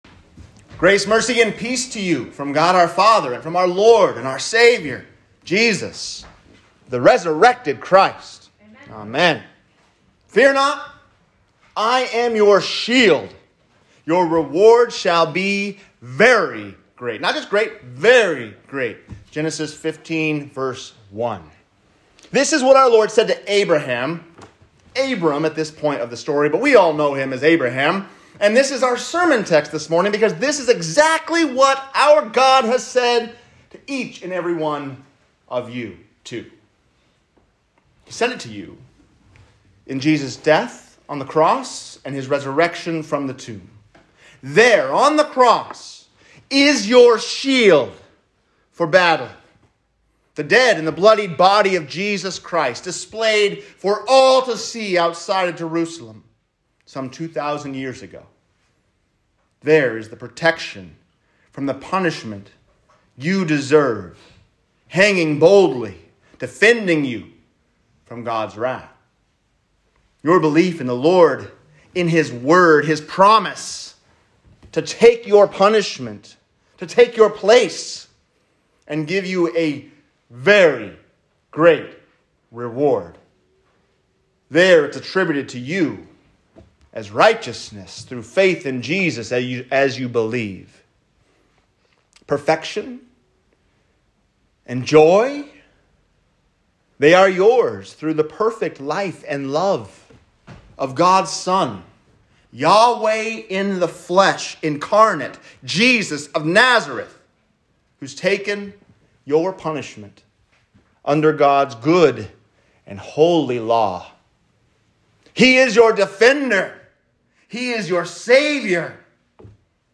Fear Not, I Am Your Shield | Sermon